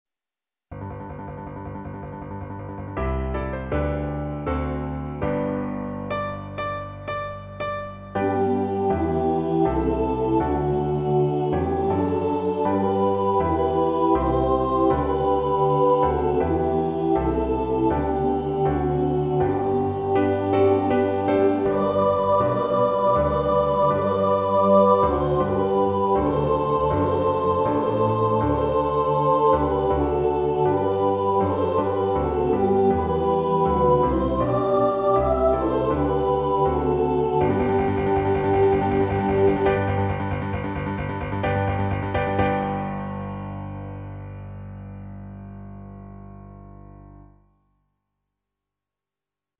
Video capture from 'Iolanthe' (1998)
At the beginning of each Durham Savoyards performance, the audience welcomes the Queen into her royal box with the singing of the National Anthem of Great Britain, "God Save the Queen," accompanied by the orchestra, with the able vocal assistance of the Lords and Ladies of the Royal Chorus in attendance in the box seats, along with unseen vocal support of the cast and company gathered onstage behind the curtain.